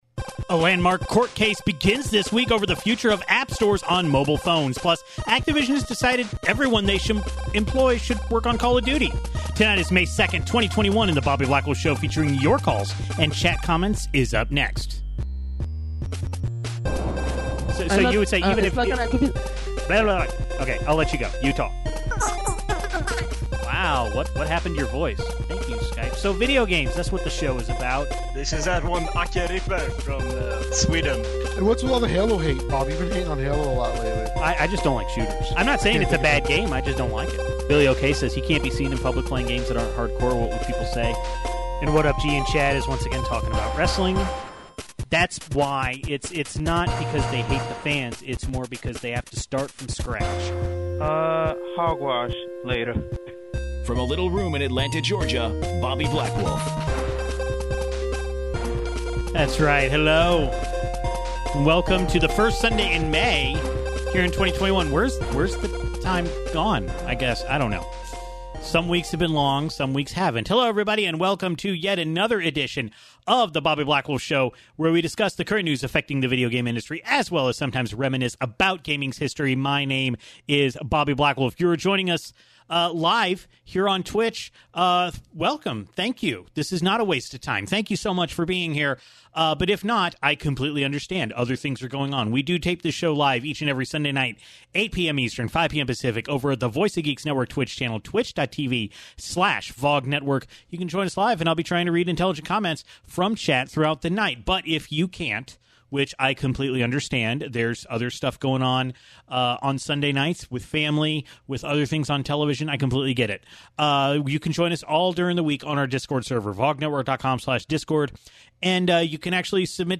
Activision has reassigned most of its studios to work on Call of Duty: Warzone rather than the IP's that made them famous. Then we take a call about New Pokemon Snap as well as previewing the Epic Games v. Apple legal fight.